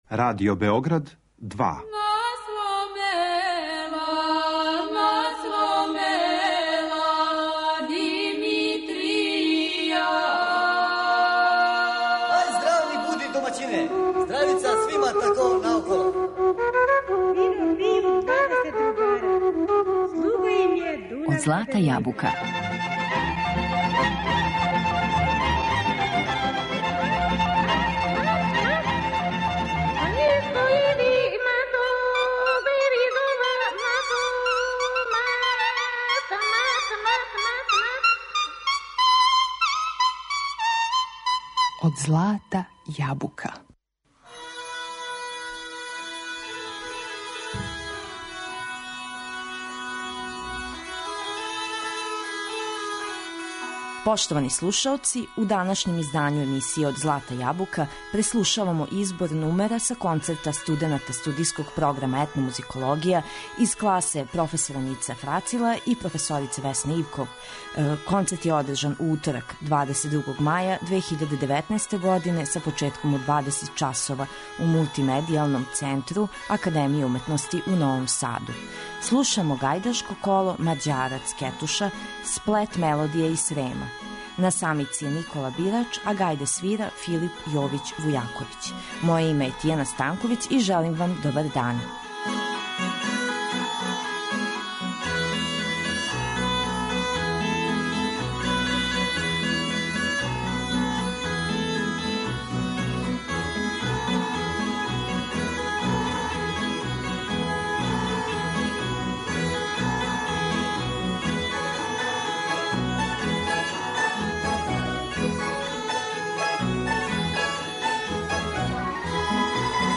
Са концерта студената студијског програма Етномузикологија Академије уметности у Новом Саду.